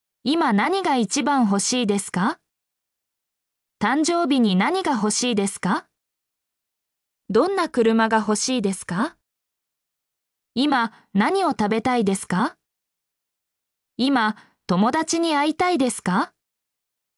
mp3-output-ttsfreedotcom-7_ainsmzBr.mp3